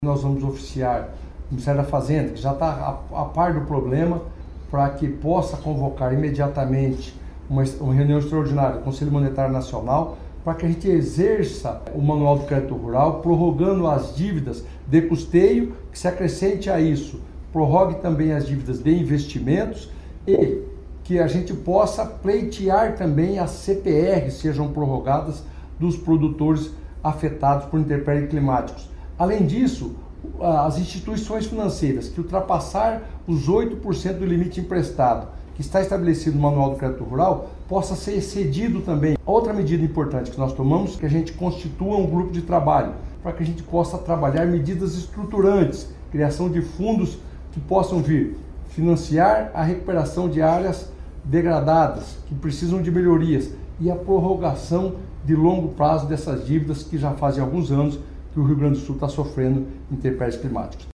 O ministro informou alguns encaminhamentos. Abaixo, manifestação do ministro Fávaro.